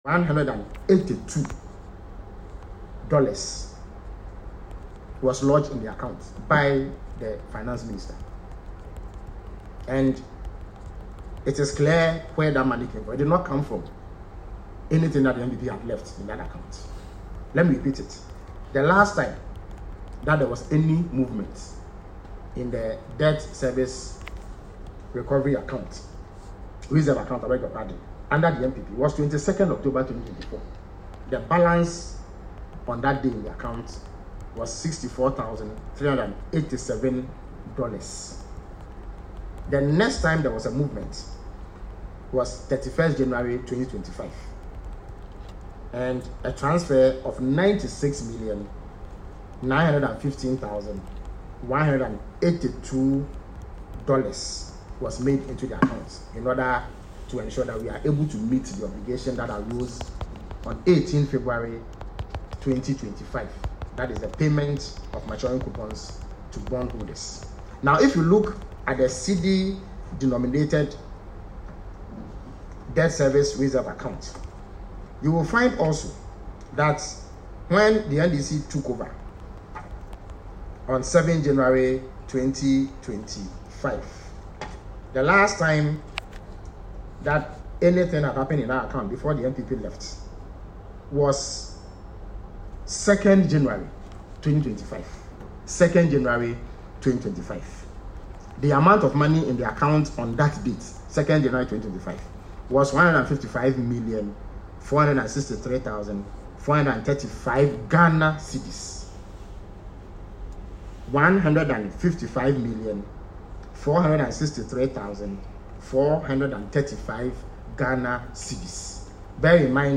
In a press briefing on Wednesday, February 19, he challenged NPP officials to specify the location of these buffers.